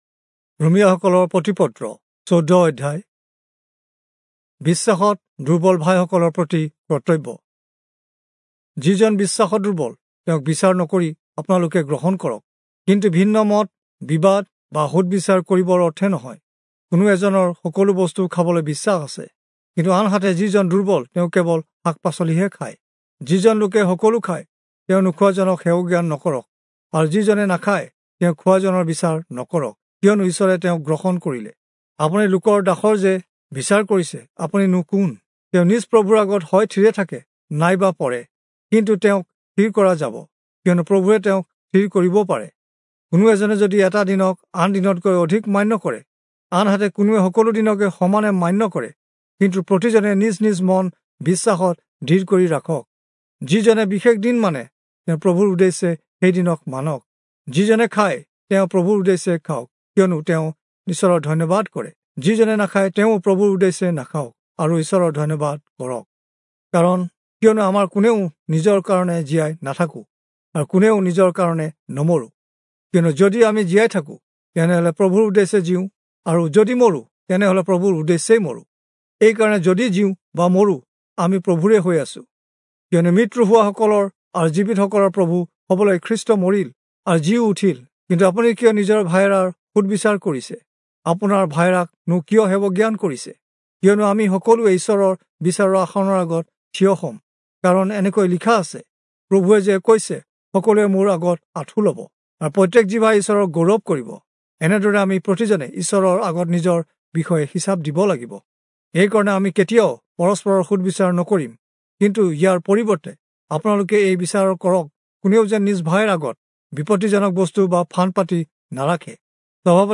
Assamese Audio Bible - Romans 15 in Ervbn bible version